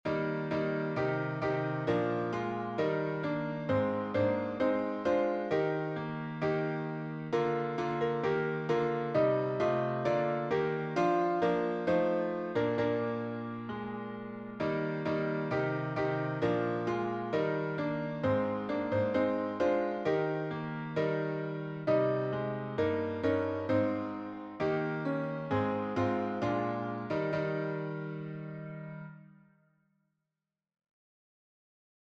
Sacred